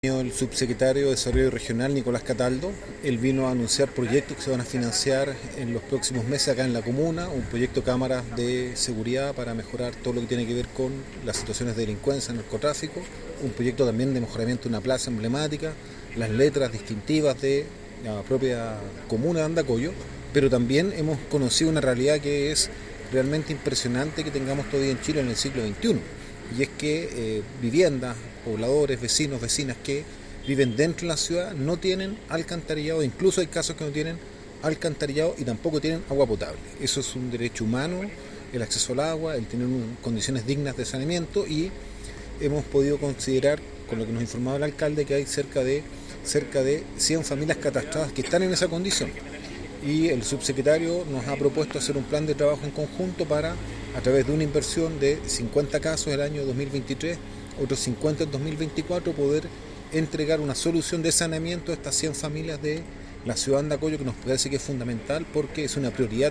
El Senador Daniel Núñez, en tanto, comentó también otros anuncios relevantes que la Subdere va a financiar en los próximos meses;
Senador-Nunez-solucionessanitarias.wav